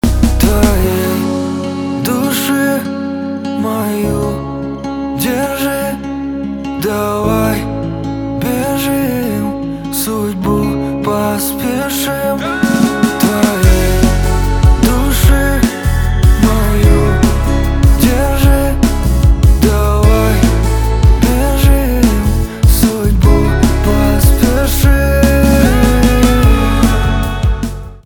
поп
гитара , нарастающие , барабаны , чувственные